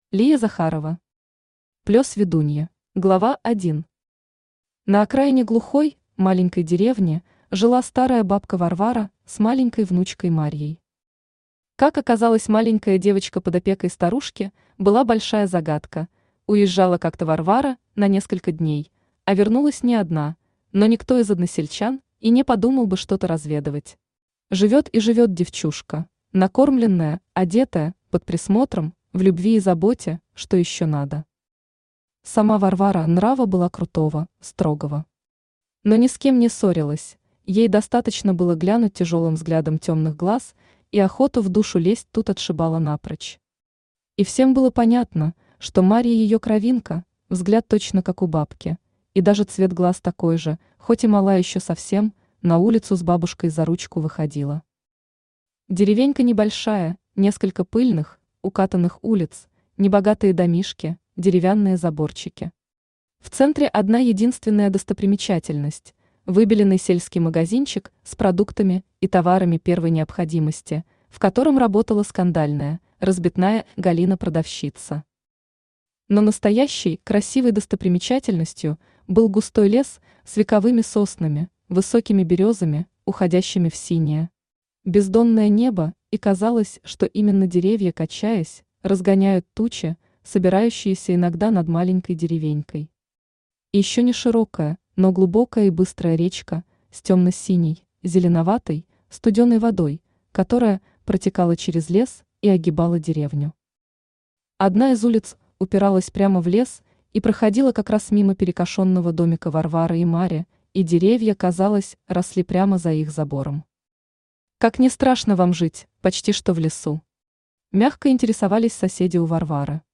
Аудиокнига Плёс ведуньи | Библиотека аудиокниг
Aудиокнига Плёс ведуньи Автор Лия Захарова Читает аудиокнигу Авточтец ЛитРес.